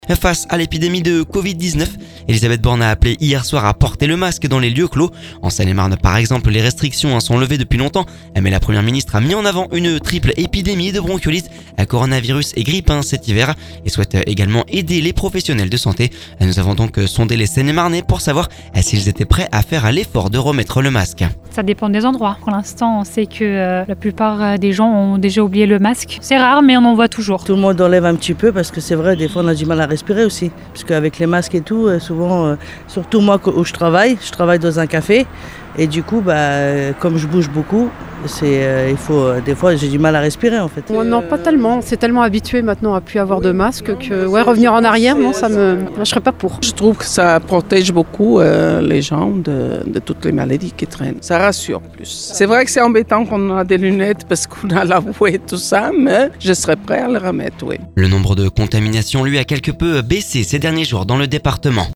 En Seine-et-Marne, les restrictions sont levées depuis longtemps mais la Première ministre a mis en avant une triple épidémie de bronchiolite, coronavirus et grippe cet hiver et souhaite aider les professionnels de santé. Nous avons sondé les Seine-et-Marnais hier pour savoir si ils étaient prêts à faire l’effort de remettre le masque..